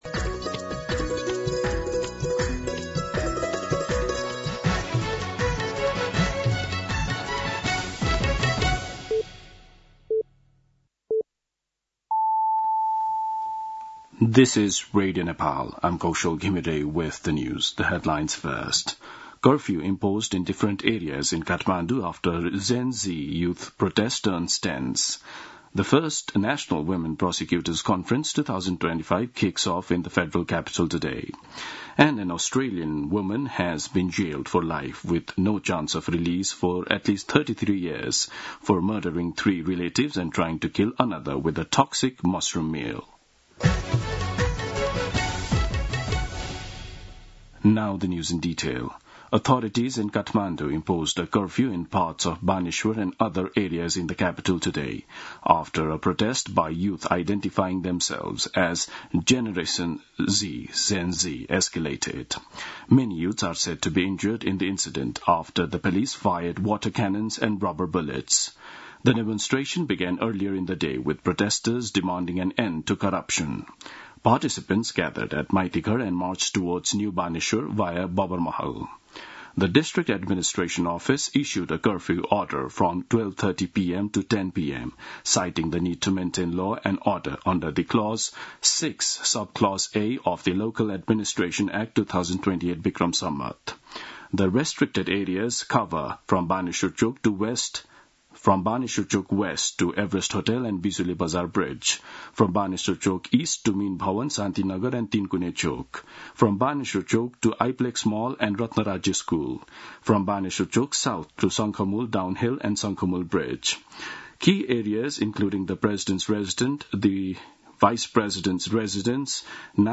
दिउँसो २ बजेको अङ्ग्रेजी समाचार : २३ भदौ , २०८२
2pm-English-News-3.mp3